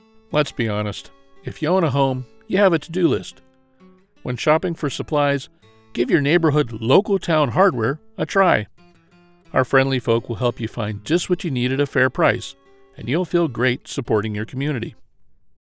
My Narration and Comercial voice is generally described as Trustworthy, Friendly, and Warm.
A quick faux commercial as a sample of recording from my booth. I looped in a public domain guitar sample as a backing.
SampleBoothSelfCommercial.mp3